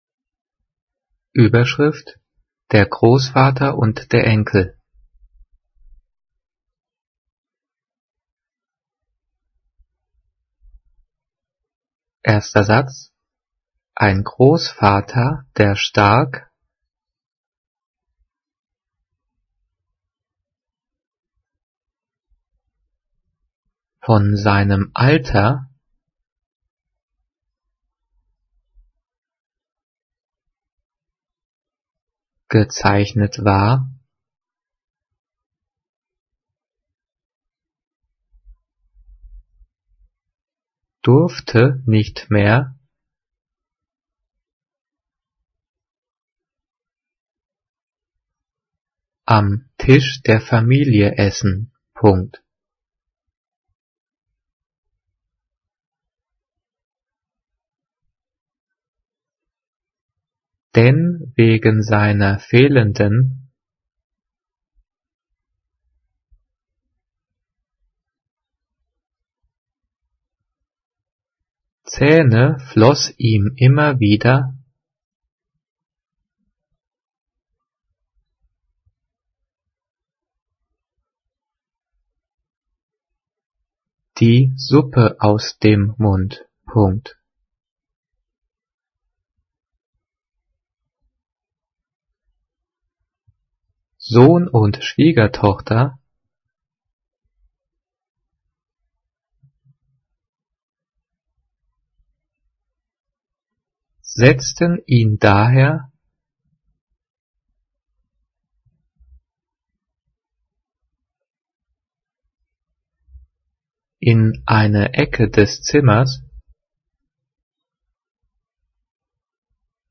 Diktiert: